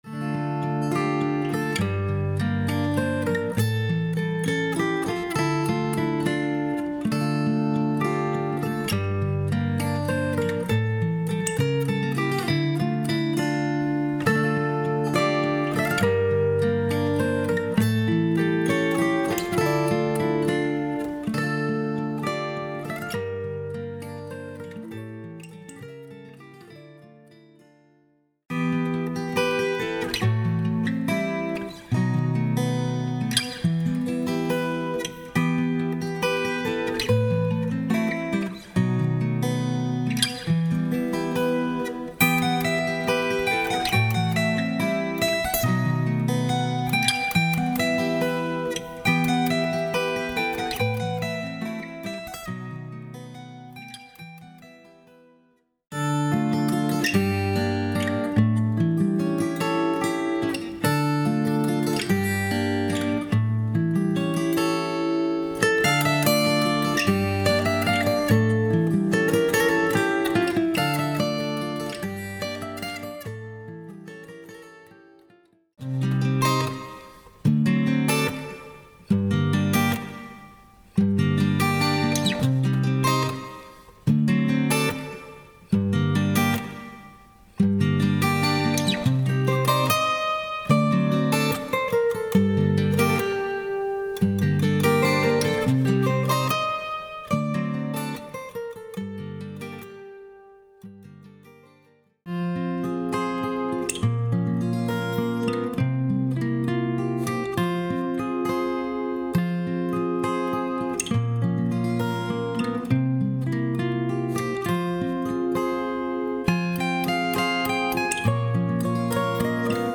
مجموعه ملودی گیتار ترپ